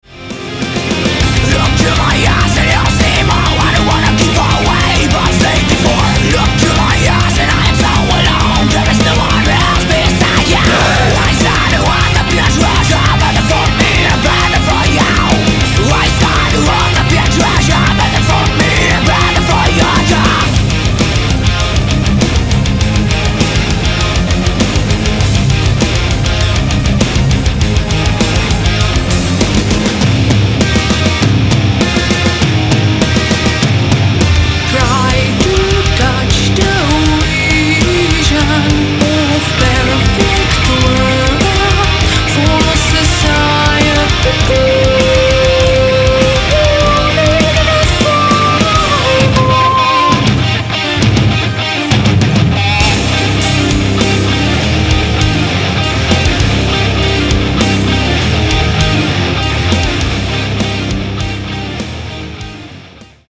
Nahrávací studio v Lipově audio / digital